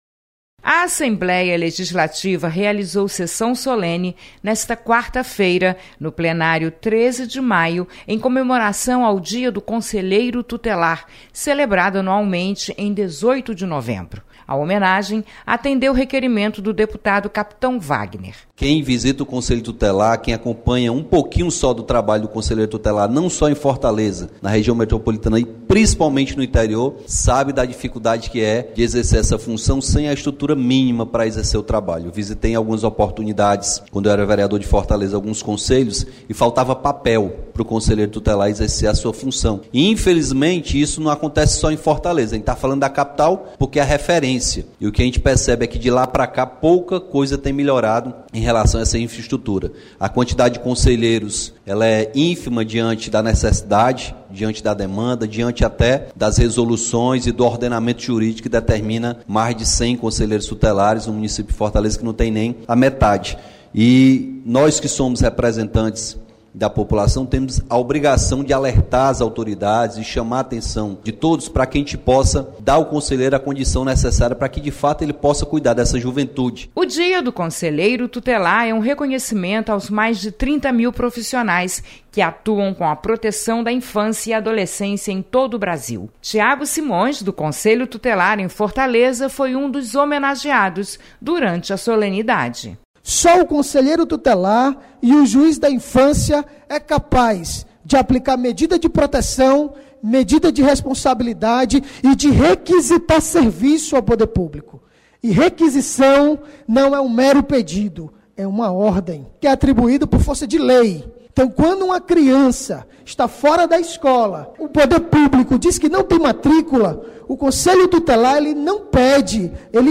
Você está aqui: Início Comunicação Rádio FM Assembleia Notícias Sessão solene